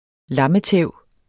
Udtale [ ˈlɑməˌtεˀw ]